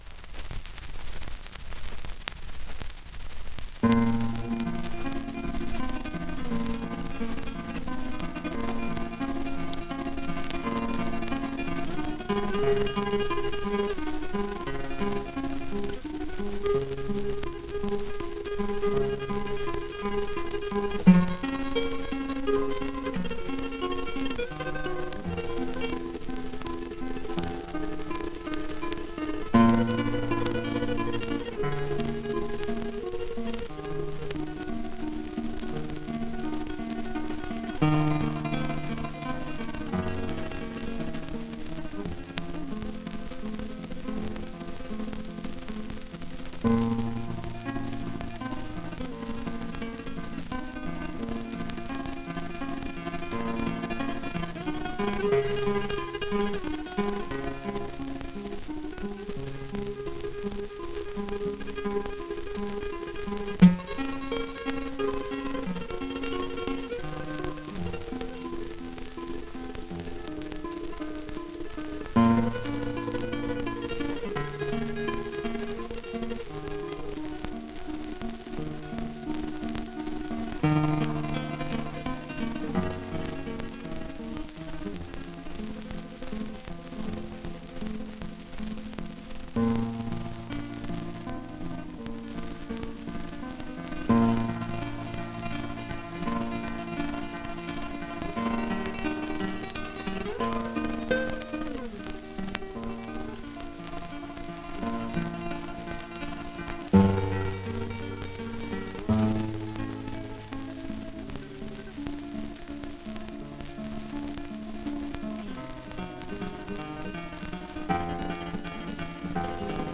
• Guitarra